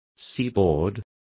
Complete with pronunciation of the translation of seaboard.